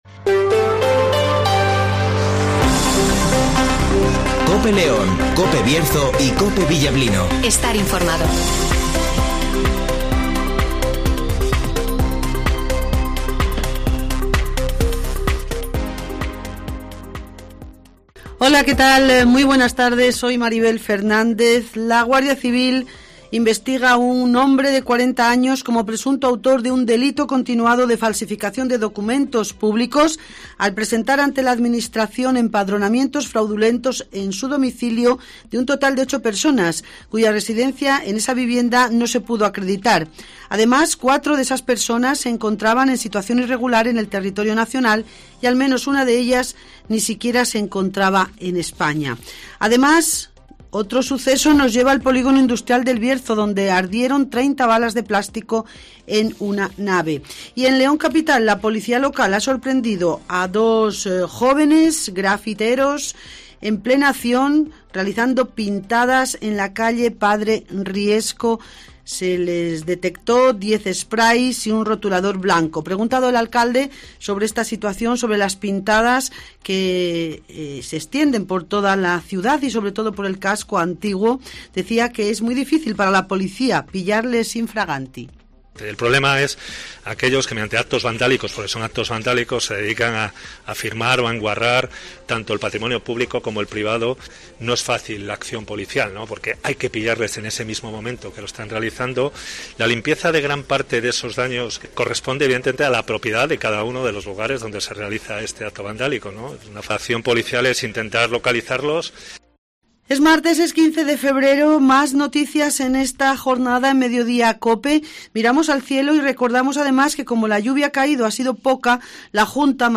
- José Antobio Díez ( Alcalde de León )